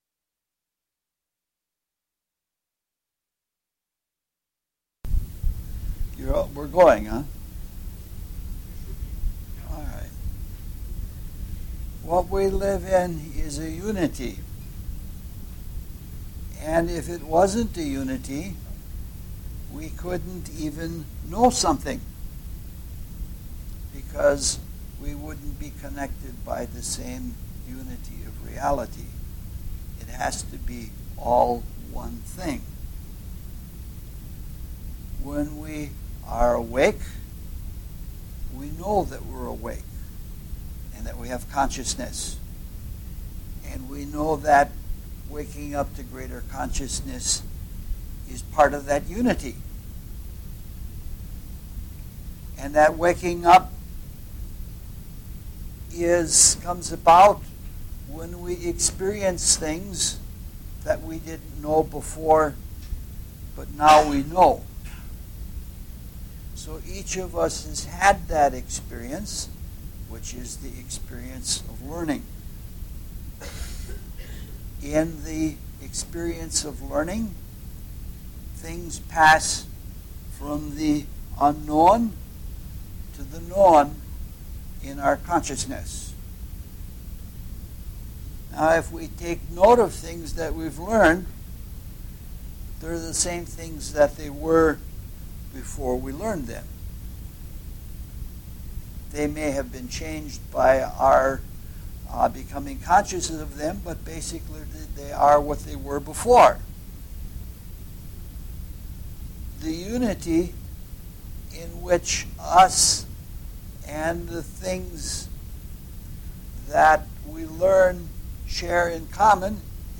LECTURE SERIES